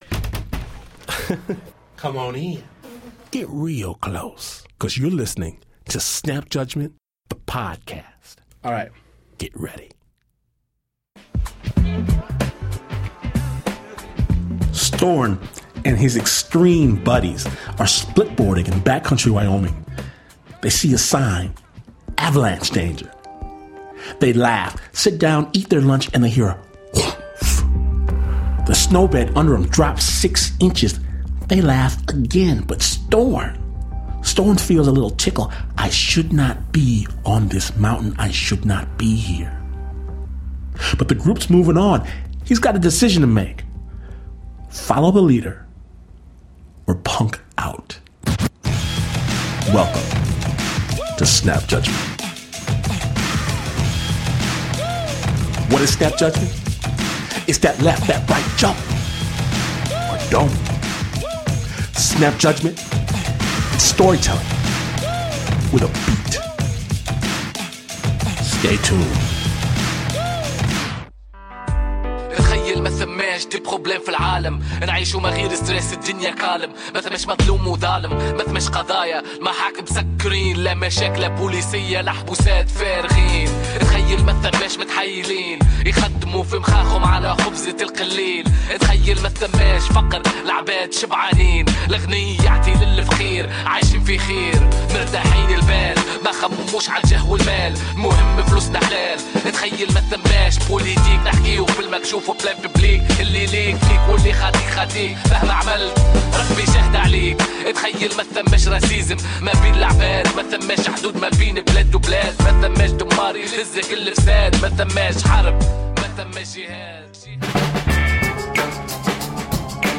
Snap Judgment (Storytelling, with a BEAT) mixes real stories with killer beats to produce cinematic, dramatic, kick-ass radio. Snap’s raw, musical brand of storytelling dares listeners to see the world through the eyes of another.